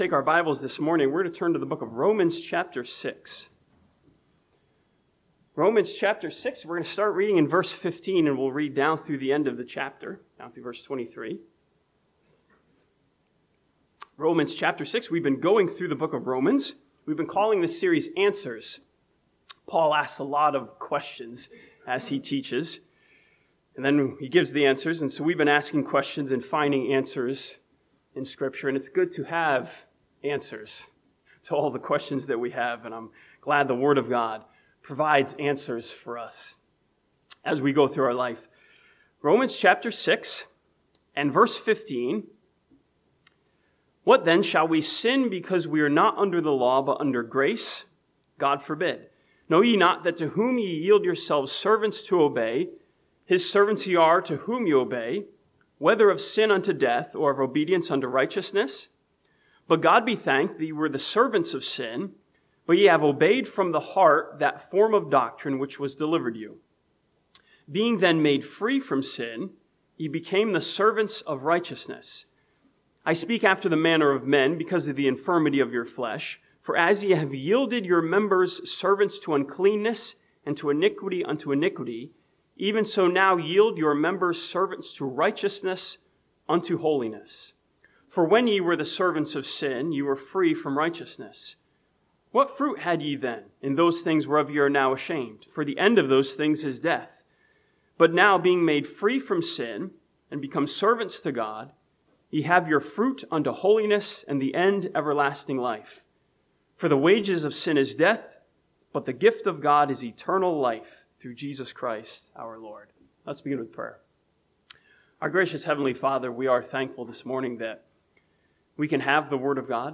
This sermon from Romans chapter 6 asks a question of results, " what is our service producing?"